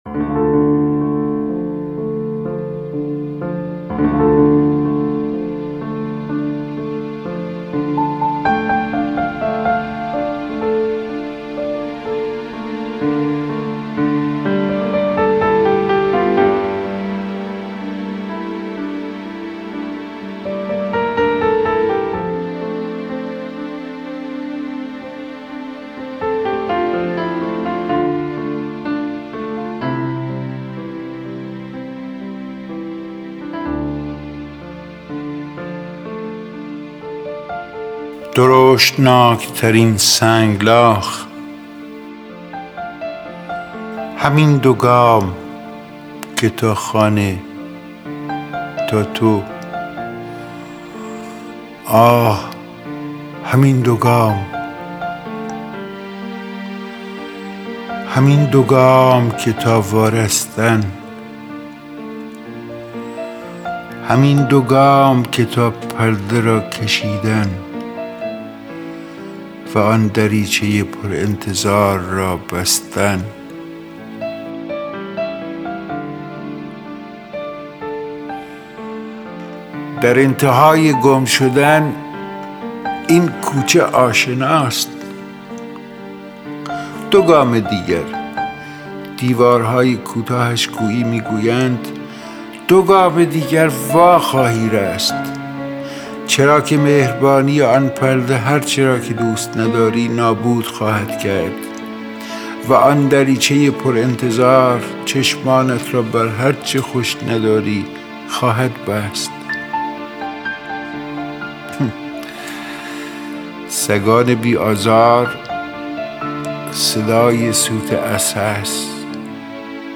دانلود دکلمه درشتناک ترین سنگلاخ با صدای اسماعیل خویی با متن دکلمه
گوینده :   [اسماعیل خویی]